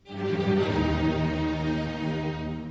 Bum Sound Effects MP3 Download Free - Quick Sounds